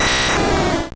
Cri de Dracolosse dans Pokémon Or et Argent.